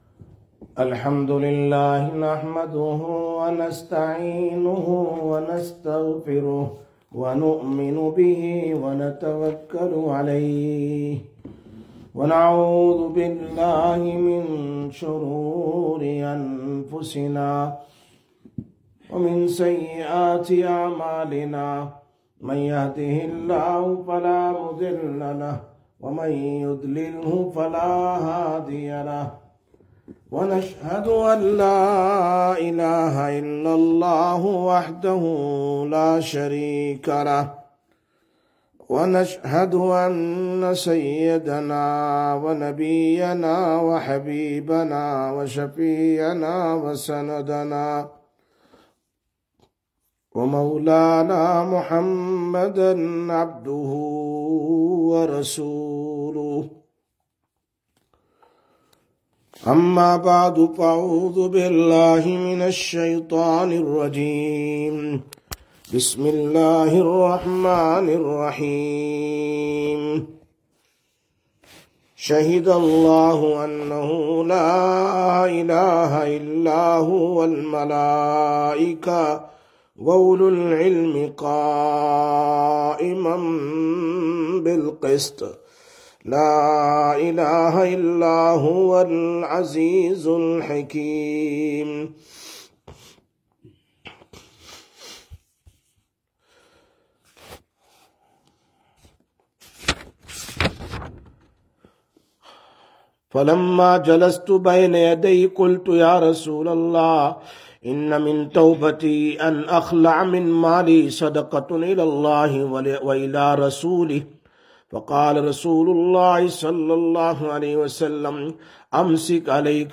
02/04/2025 Sisters Bayan, Masjid Quba